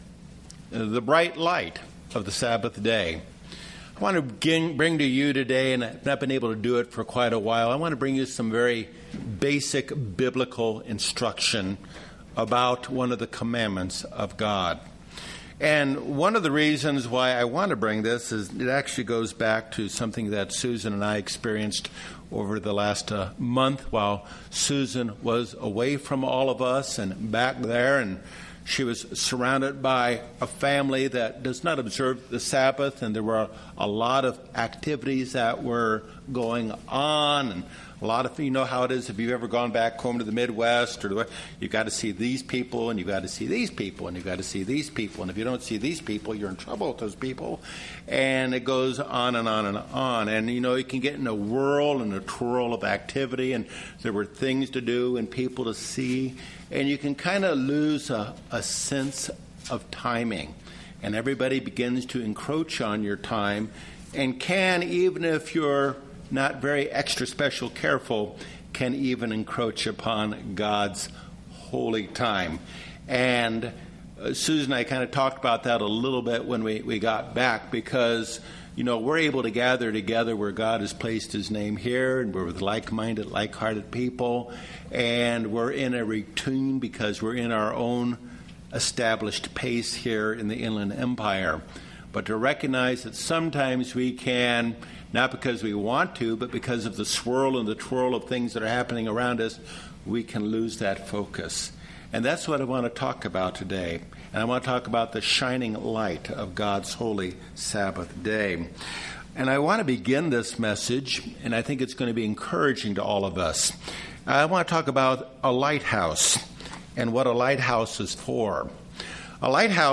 The Sabbath is a day of liberation Deuteronomy 5:15 The Sabbath brings delight, Isaiah 58:14-14 UCG Sermon Transcript This transcript was generated by AI and may contain errors.